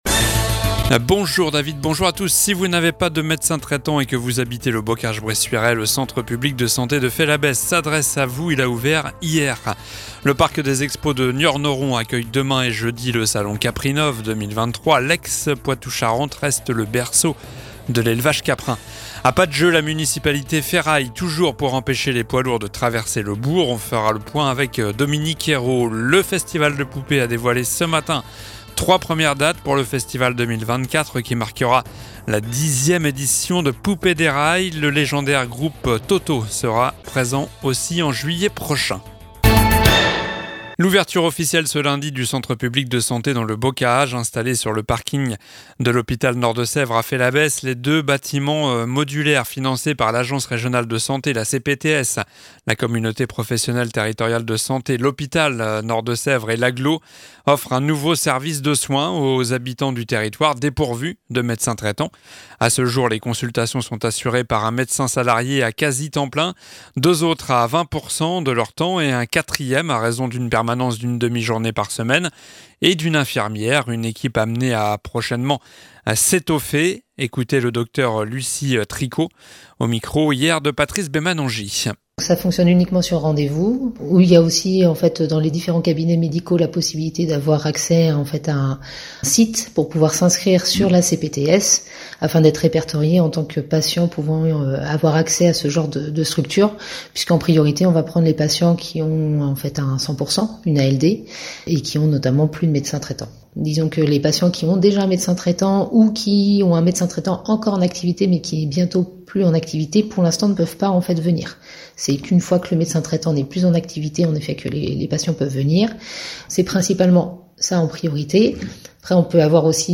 Journal du mardi 21 novembre (midi)